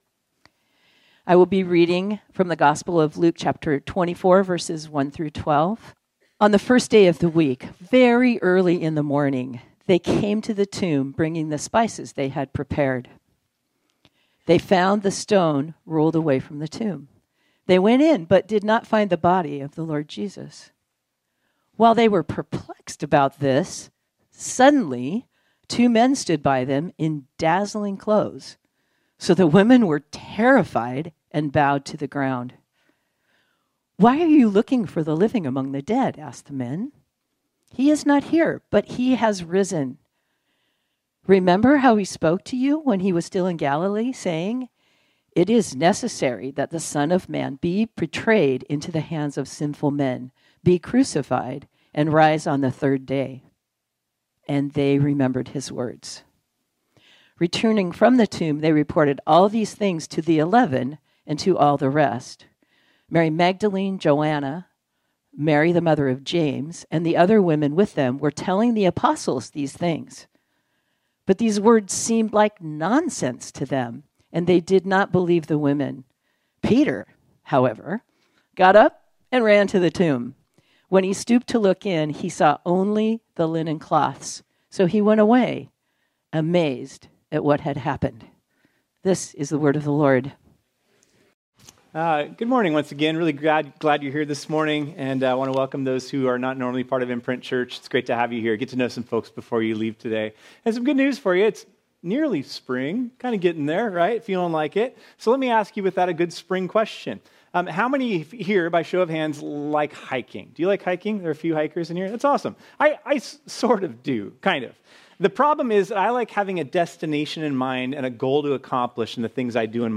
This sermon was originally preached on Sunday, April 20, 2025.